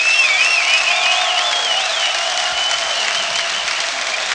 APPLAUSE.wav